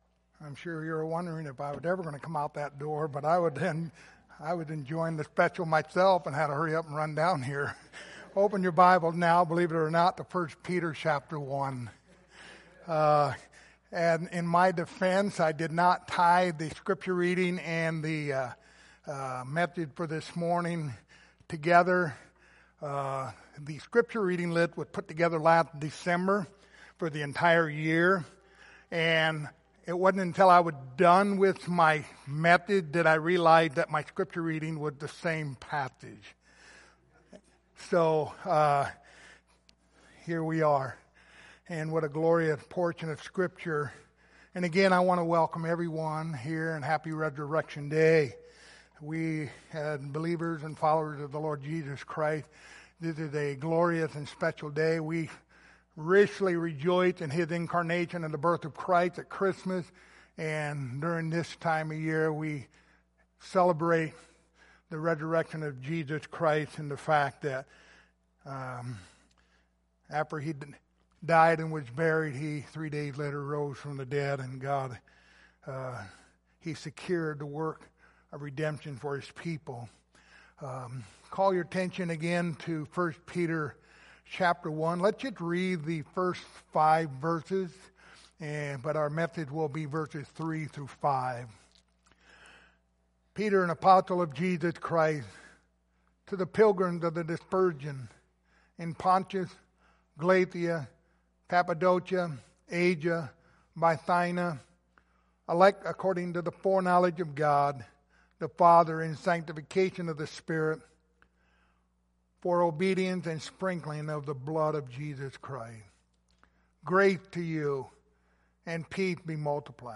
Passage: 1 Peter 1:3-5 Service Type: Sunday Morning